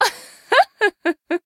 MissFortune.laugh.jp8.mp3